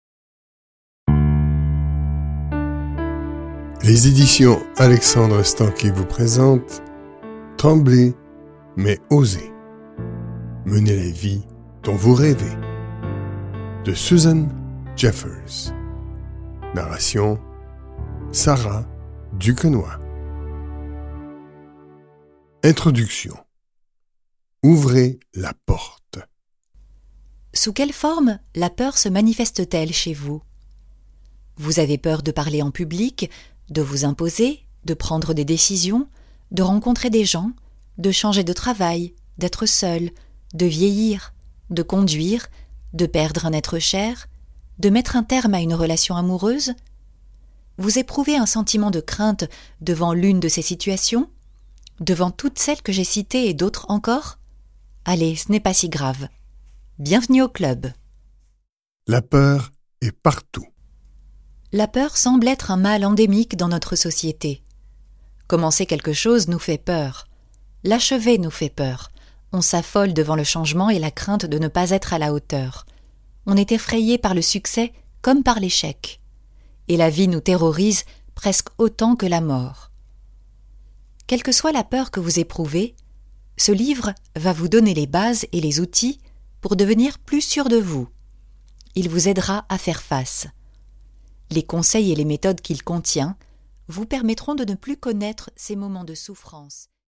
Ce livre audio vous aidera à identifier vos peurs et à les surmonter pour agir et ne plus subir.